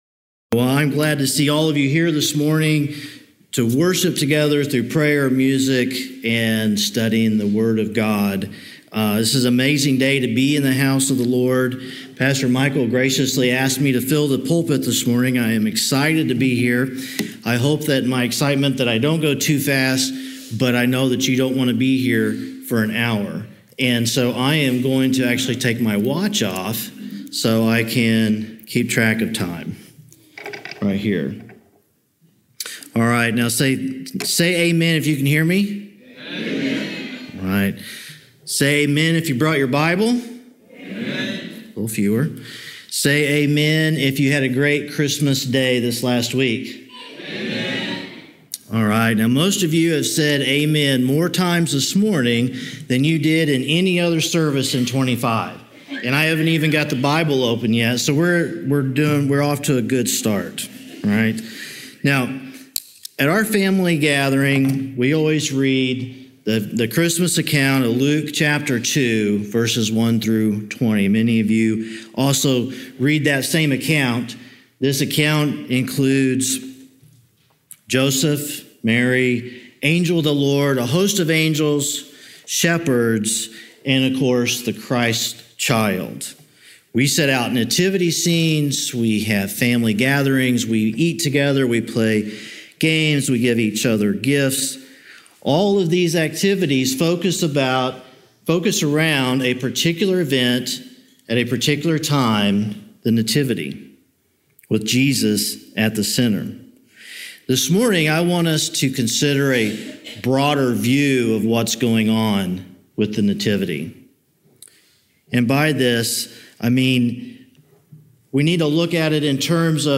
12-28-25-Sermon.mp3